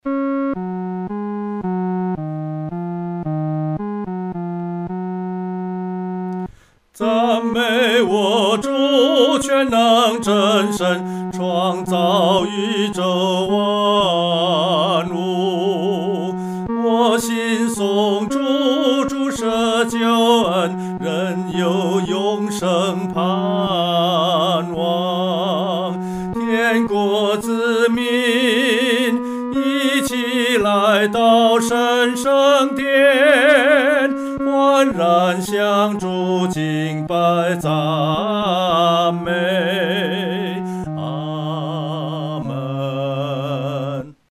独唱（第三声）
赞美全能神-独唱（第三声）.mp3